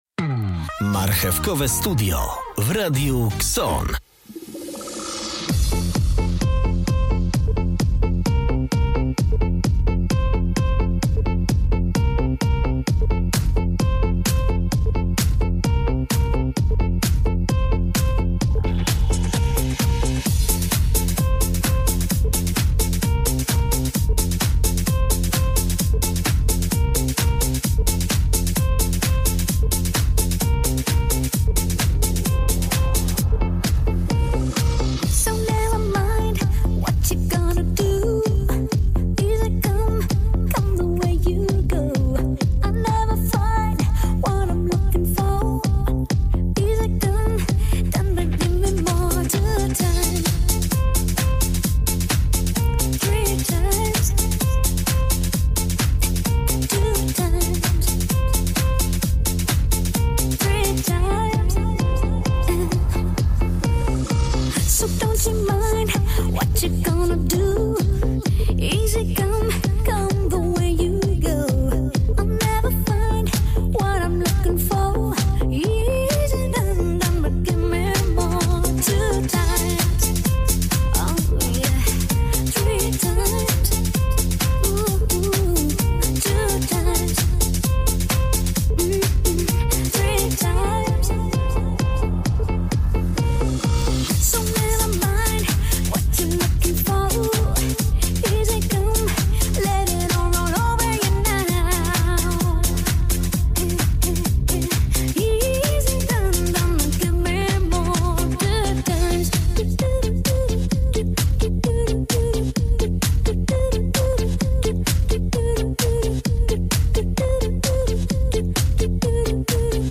Porozmawiamy o modzie przy świetnej muzyce.